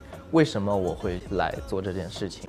Chinese_Audio_Resource / 蔡徐坤 /有背景音乐的声音 /为什么我会来做这件事情.wav